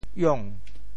“甬”字用潮州话怎么说？
iong2.mp3